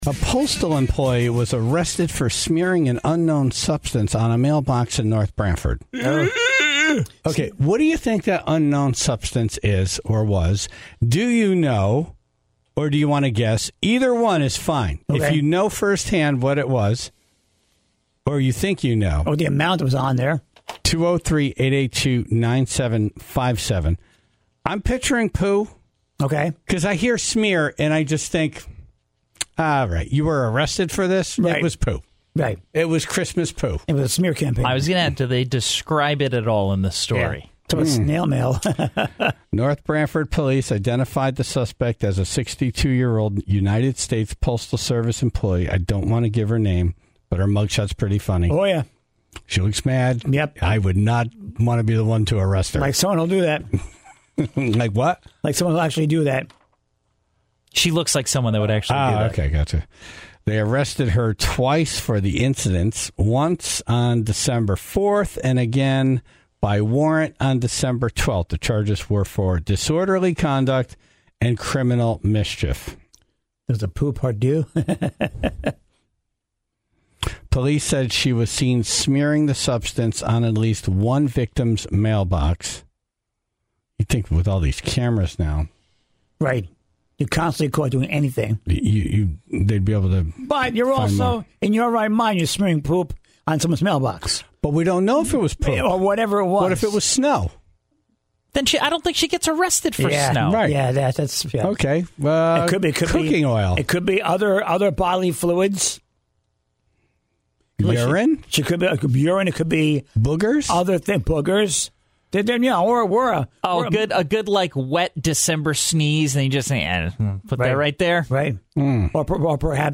after taking calls from the Tribe in Northford and North Branford.